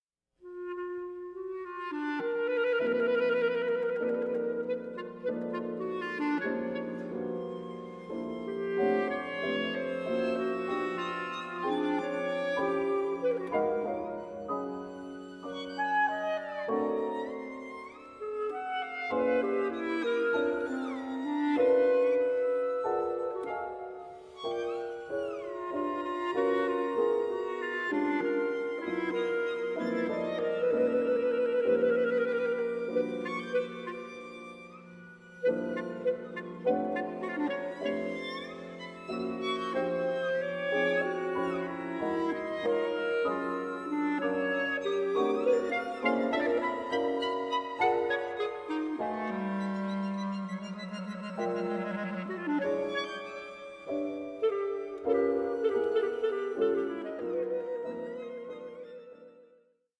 live recording, clarinetist
cellist